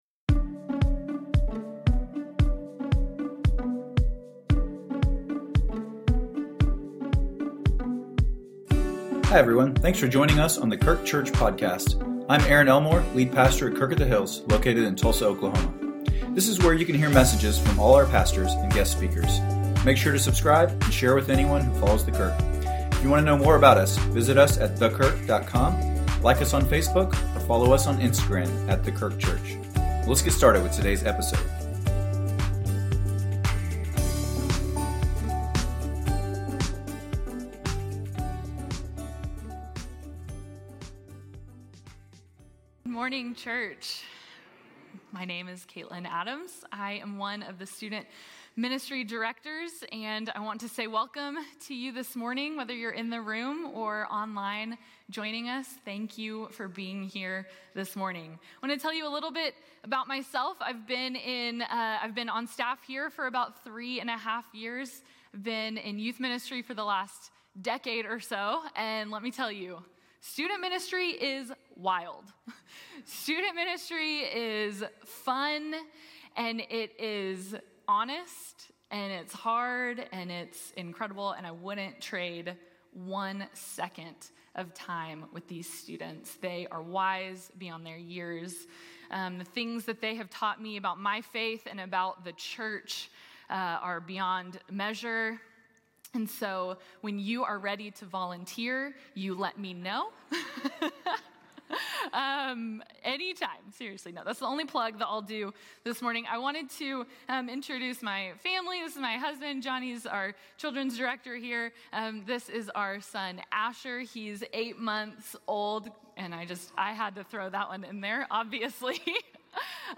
A message from the series "Faithful in the Fray."